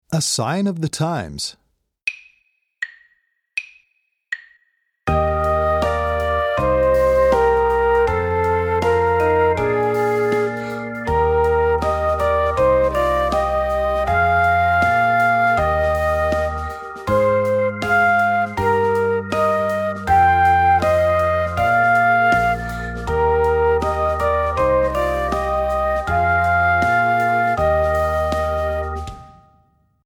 Voicing: Bass Clarinet